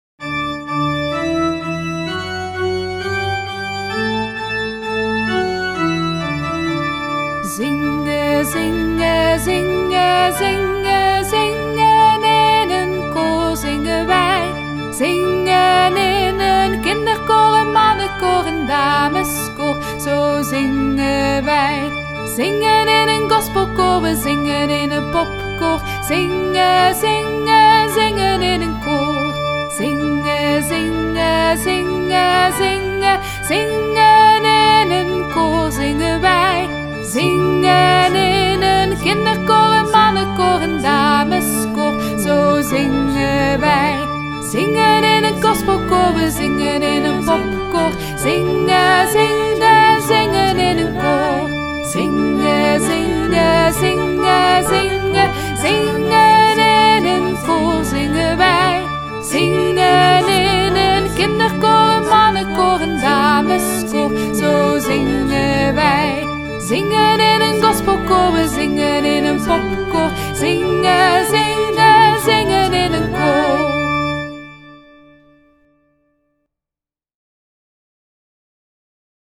Genre: Blues.
zingenineenkoor_metzang-1.mp3